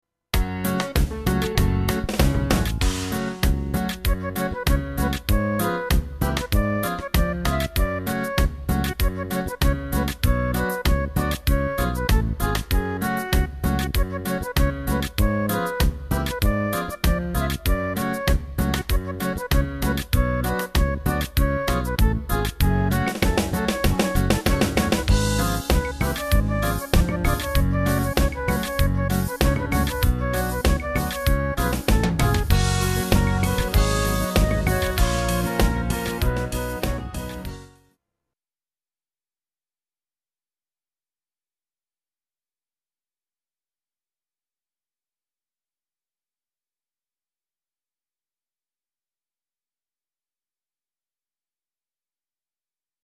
Tempo: 100 BPM.
MP3 with melody DEMO 30s (0.5 MB)zdarma